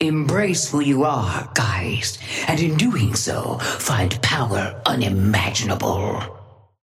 Sapphire Flame voice line - Embrace who you are, Geist, and in doing so, find power unimaginable.
Patron_female_ally_ghost_oathkeeper_5g_start_05_alt_01.mp3